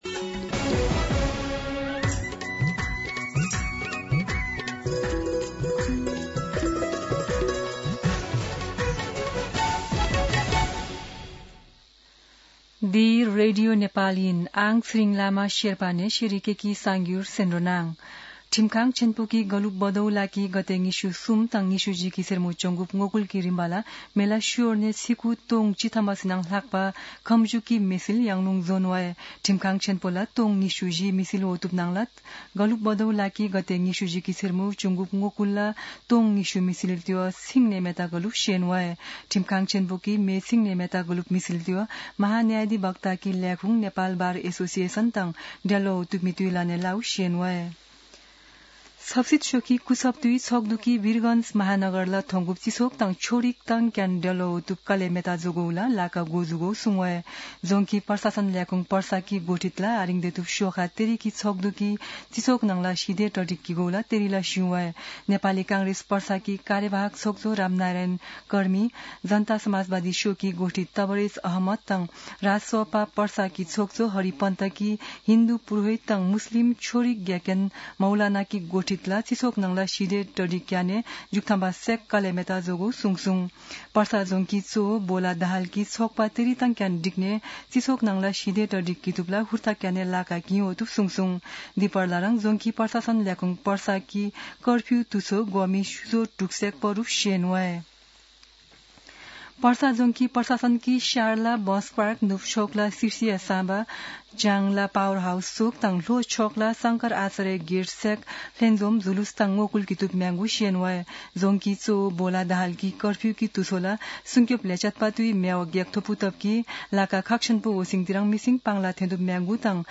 शेर्पा भाषाको समाचार : २२ पुष , २०८२
Sherpa-News-22.mp3